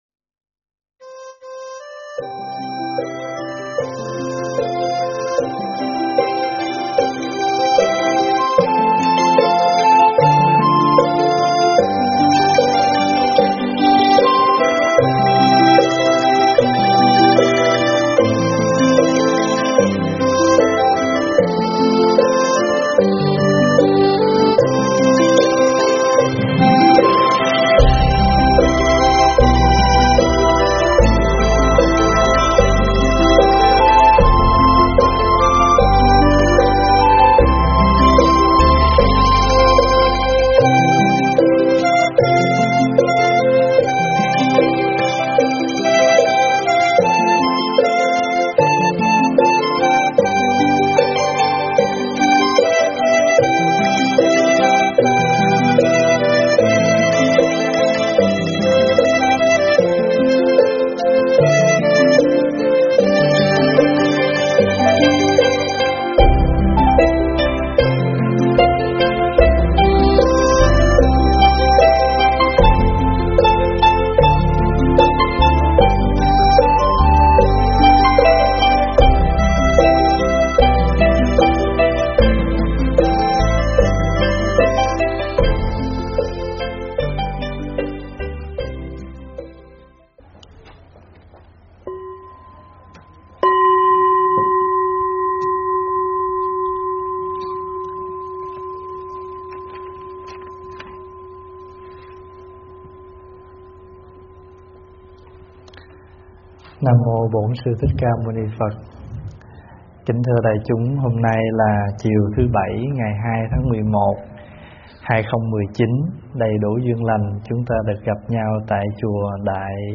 Mp3 thuyết pháp Lễ Halloween
tại Chùa Đại Nam, Hemiji, Nhật Bản